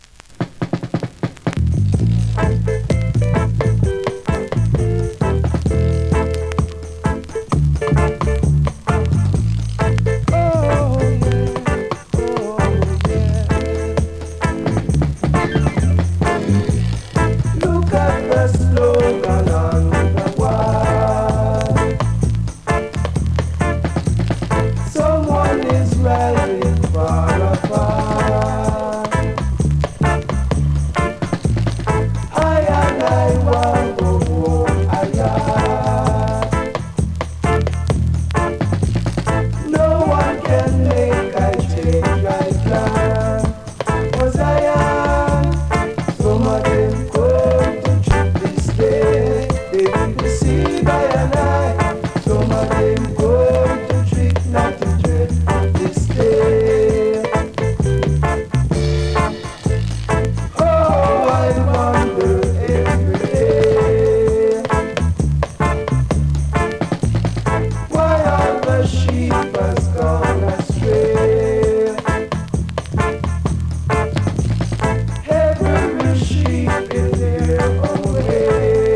NEW IN!SKA〜REGGAE
スリキズ、ノイズそこそこありますが